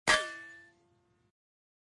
ting.2.ogg